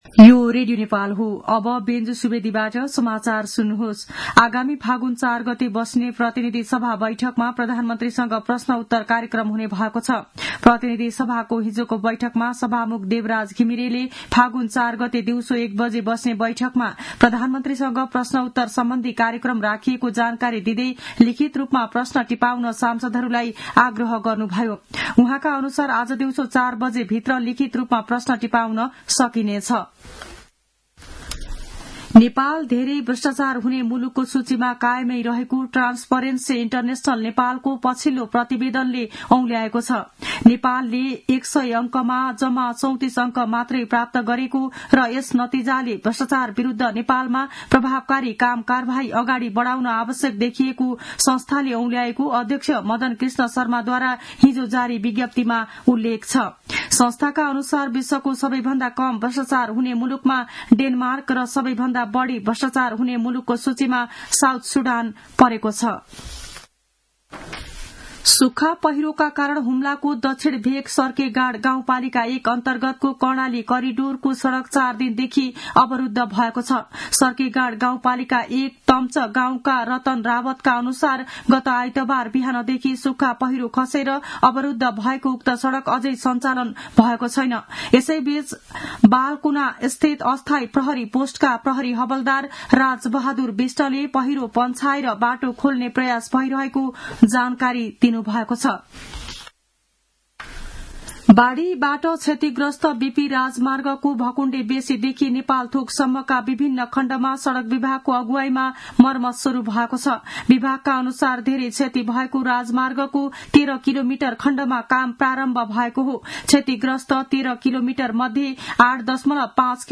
मध्यान्ह १२ बजेको नेपाली समाचार : १ फागुन , २०८१
12-am-news-1-5.mp3